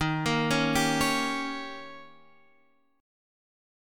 Eb7 Chord
Listen to Eb7 strummed